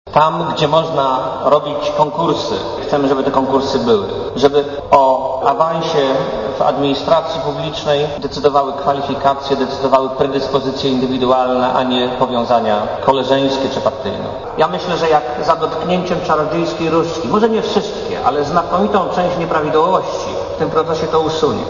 mówi premier Marek Belka